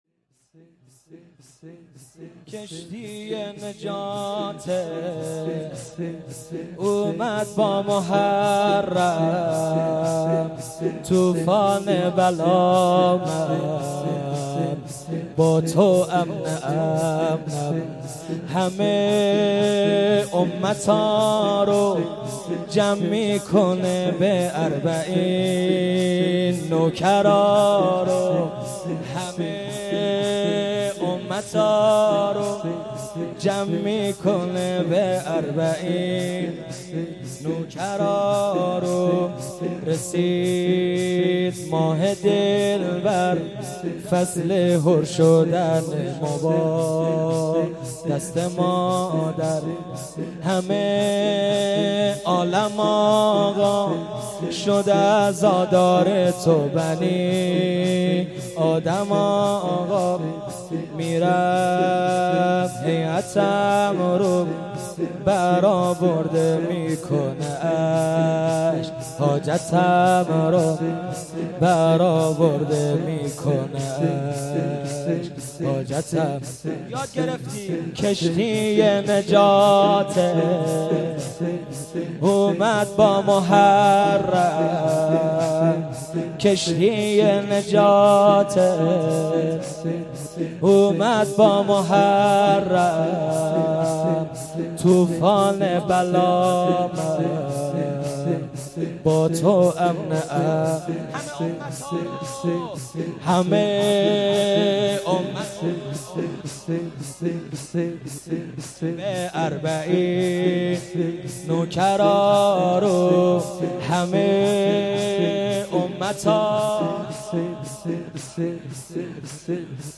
شب چهارم محرم 1398